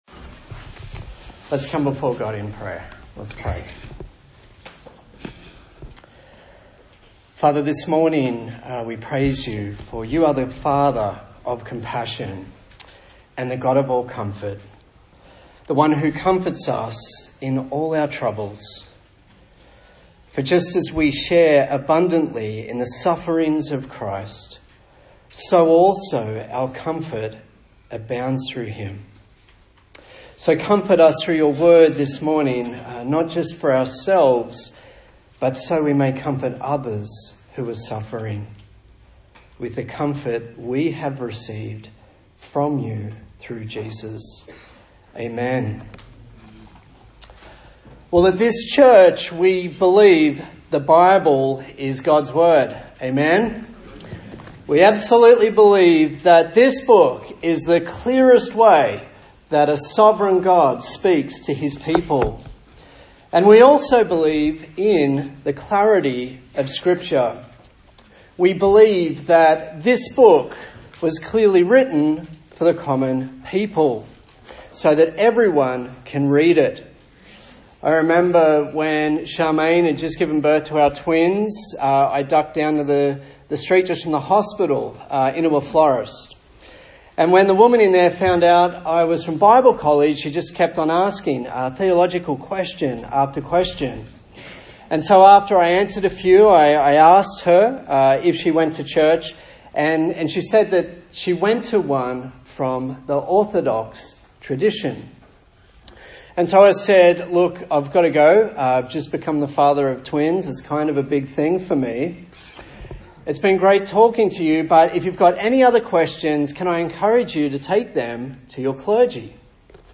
A sermon in the series on the book of 1 Peter
Service Type: Sunday Morning